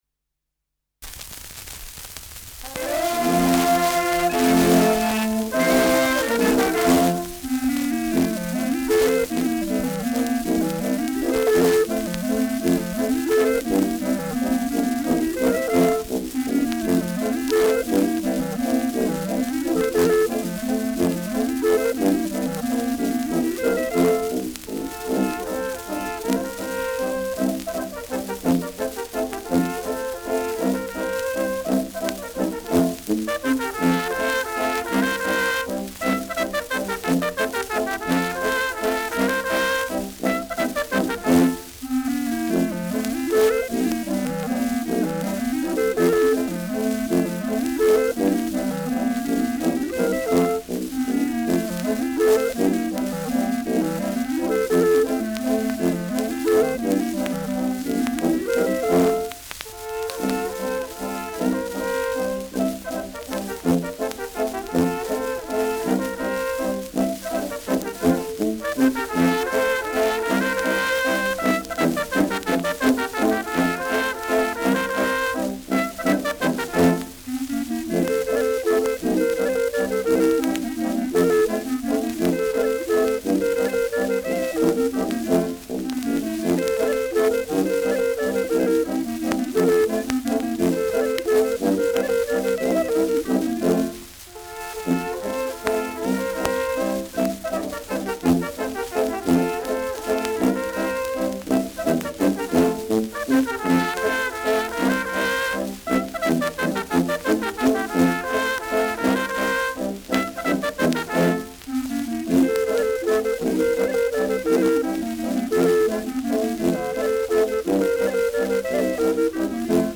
Schellackplatte
Tonrille: graue Rillen
präsentes Rauschen : leichtes Knacken
Dachauer Bauernkapelle (Interpretation)